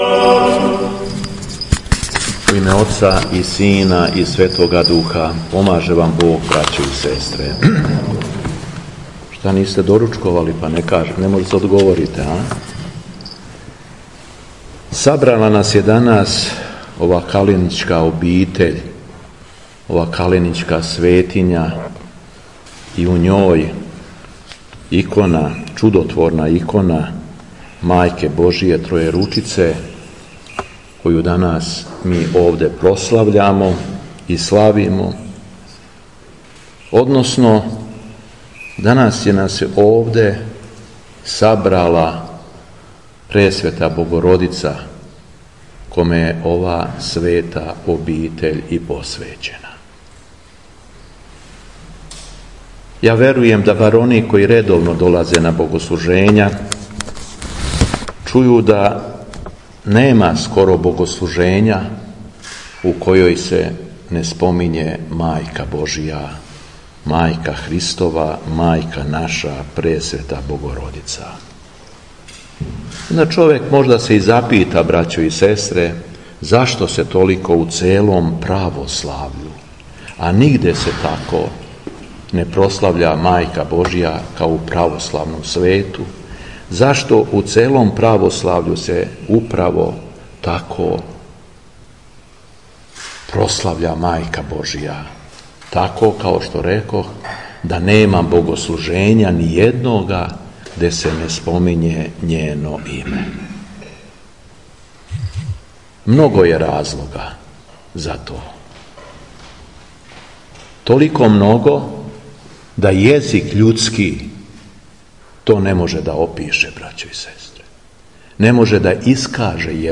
ПРОСЛАВЉАЊЕ ИКОНЕ ПРЕСВЕТЕ БОГОРОДИЦЕ ТРОЈЕРУЧИЦЕ У МАНАСТИРУ КАЛЕНИЋ - Епархија Шумадијска
Беседа Епископа шумадијског Г. Јована
Молитвено сабрање су својим слаткопојем улепшали чланови певачког друштва “Србски православни појци” из Београда.